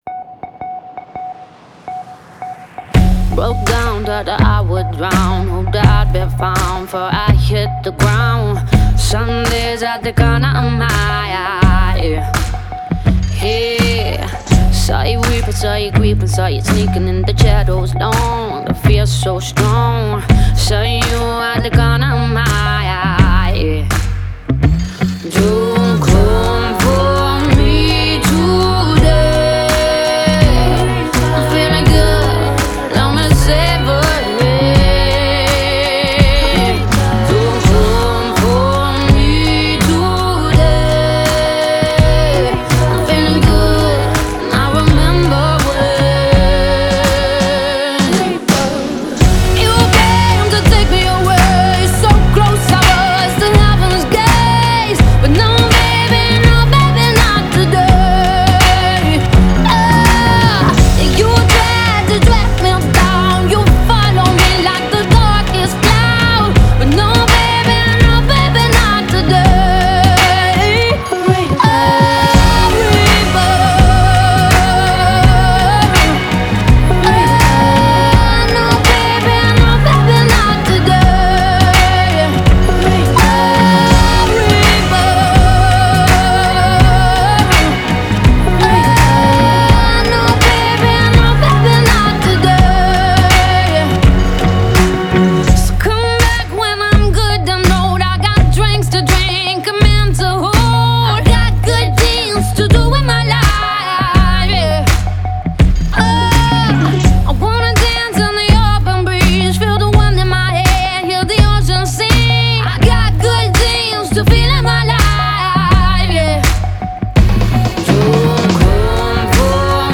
Genre: Alternative, Pop